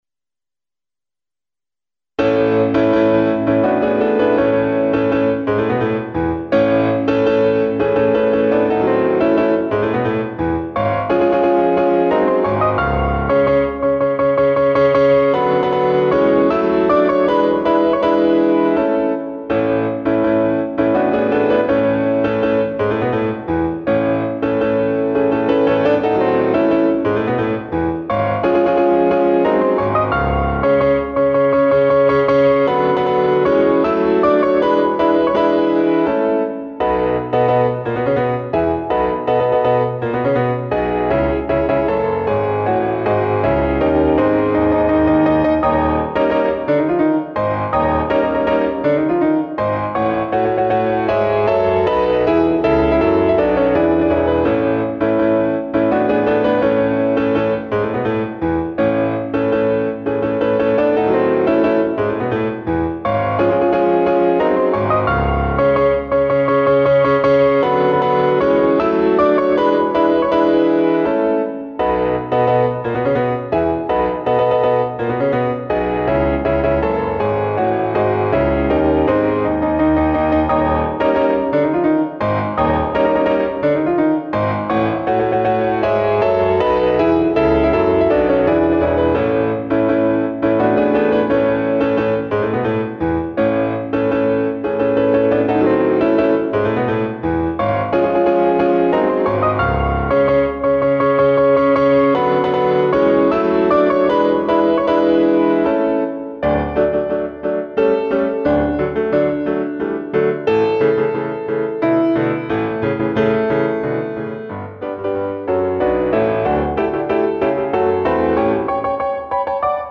Polonaise No. 03 in A Major Op. 40-1
made with "Miroslav Philharmonik"
CLASSICAL MUSIC